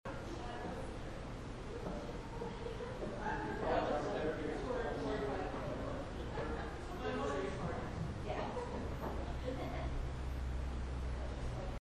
Audio recordings revealed some possible EVPs (electronic voice phenomena) and unusual noises which are included on a CDROM.
A woman is heard singing as a tour group is exiting the stage.